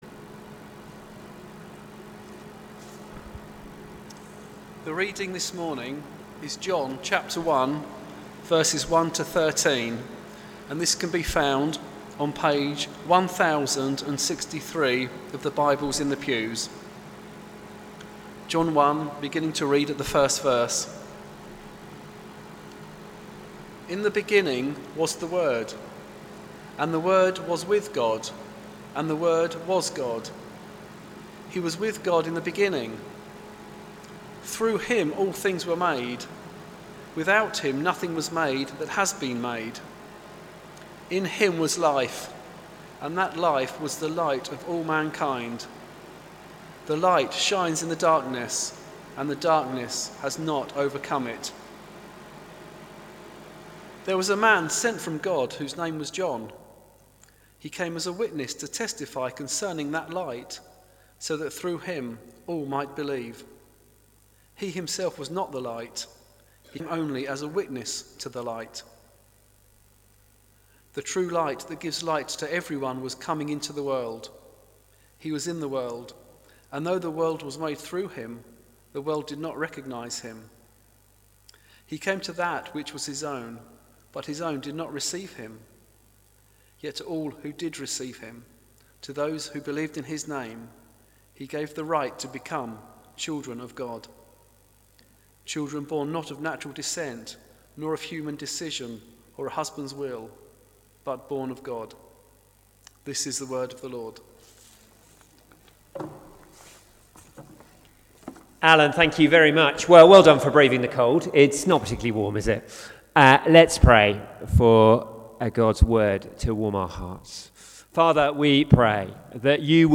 The video spoken of in this sermon is below.